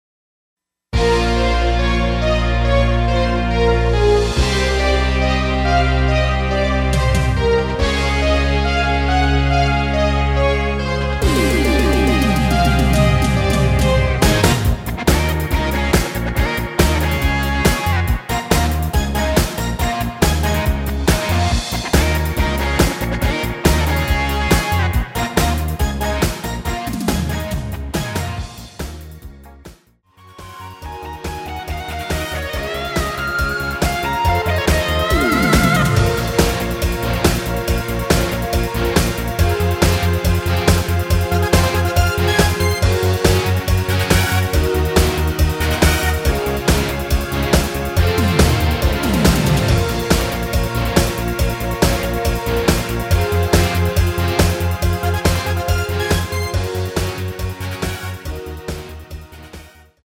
Bbm
◈ 곡명 옆 (-1)은 반음 내림, (+1)은 반음 올림 입니다.
앞부분30초, 뒷부분30초씩 편집해서 올려 드리고 있습니다.
중간에 음이 끈어지고 다시 나오는 이유는